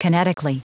Prononciation du mot kinetically en anglais (fichier audio)
Prononciation du mot : kinetically